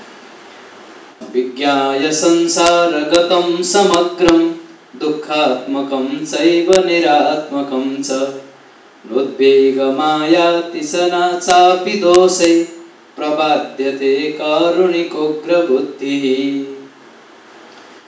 upajāti